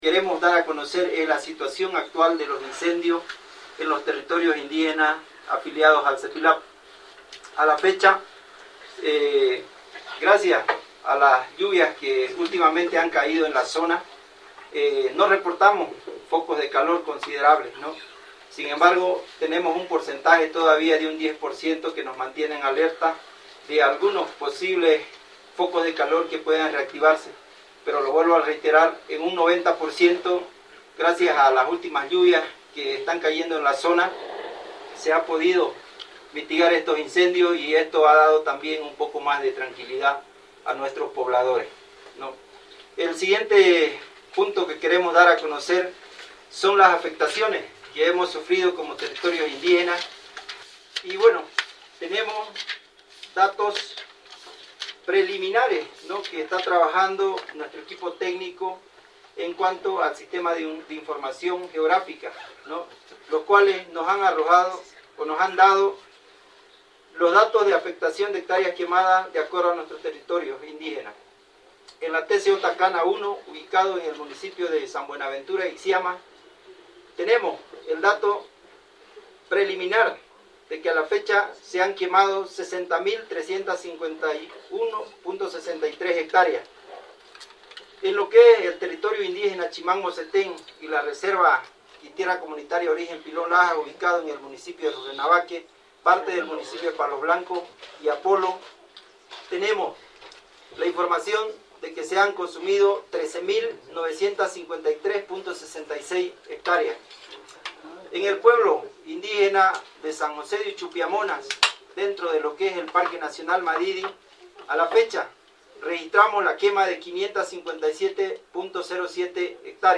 CONFERENCIA-CPILAP.mp3